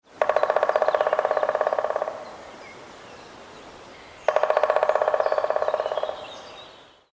Wer singt oder ruft hier?
Vogel 6
Die Tierstimmen sind alle aus dem Tierstimmenarchiv des Museum für Naturkunde - Leibniz-Institut für Evolutions- und Biodiversitätsforschung an der Humboldt-Universität zu Berlin
MH12_Vogel6.mp3